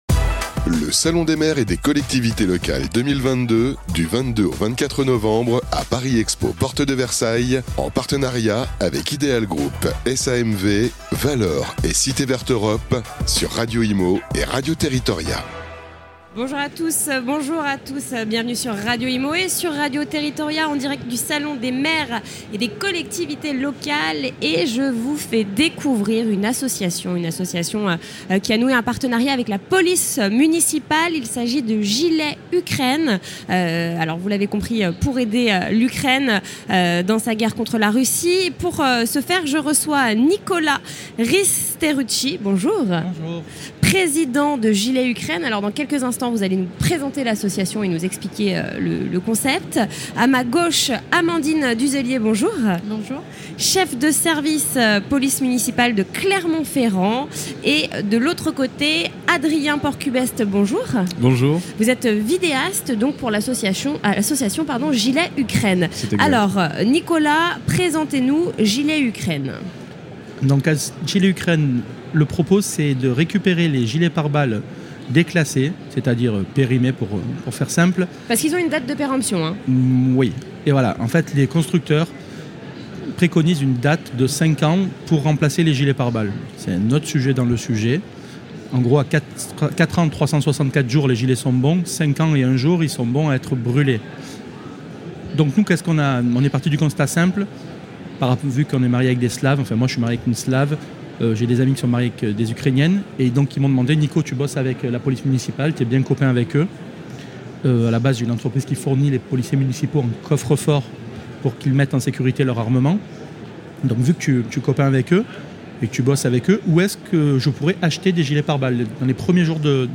Table Ronde : Smart City et Smart Grid, état des lieux et perspectives (Part. 1) - Salon Immobilier Grand Est